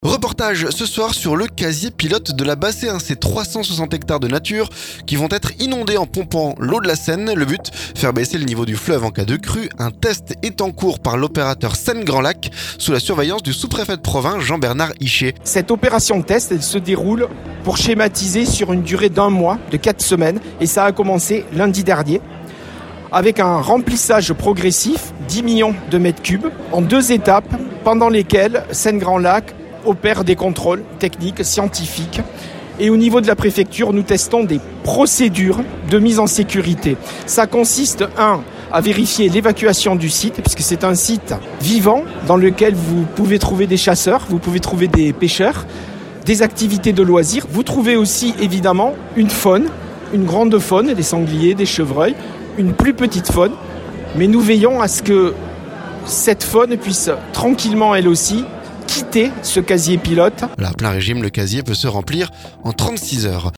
Reportage ce soir sur le casier pilote de la bassée. Ces 360 hectares de nature qui vont être inondées en pompant l'eau de la Seine. Le but : faire baisser le niveau du fleuve en cas de crue.